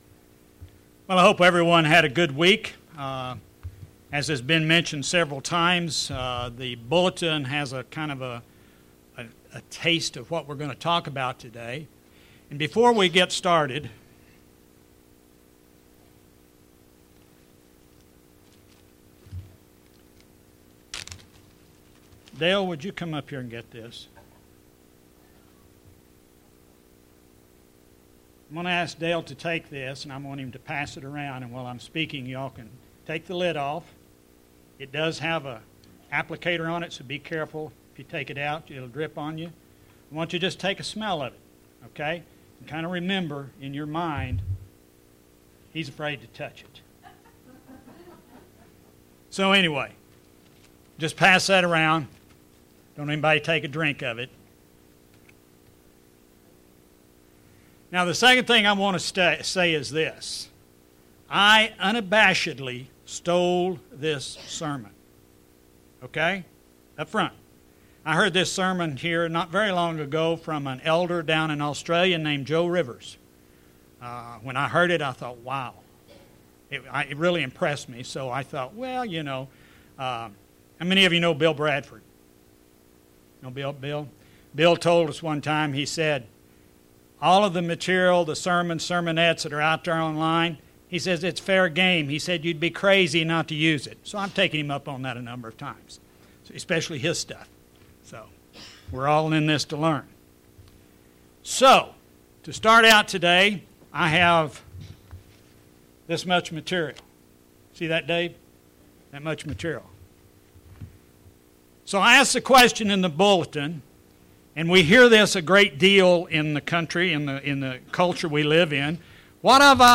In this sermon, the speaker looks at the topic of what is called the "Prosperity Gospel."